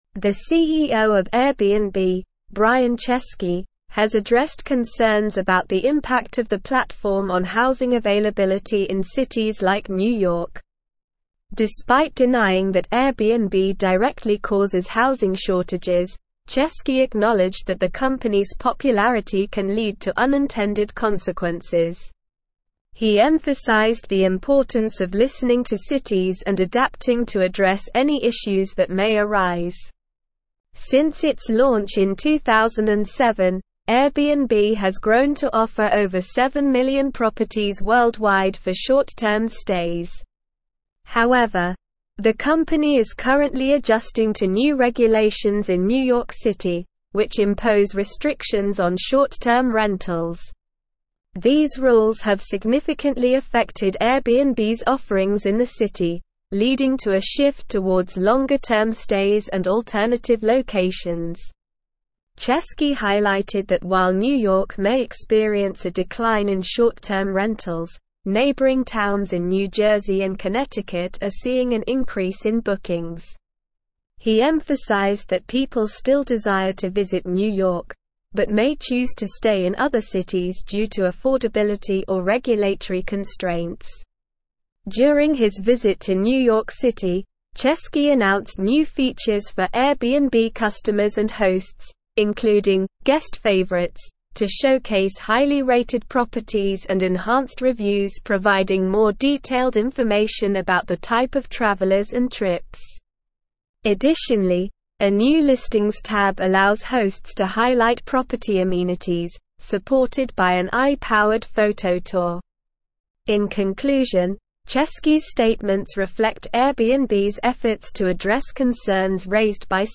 English-Newsroom-Advanced-SLOW-Reading-Airbnb-CEO-Addresses-Housing-Concerns-Adapts-to-Regulations.mp3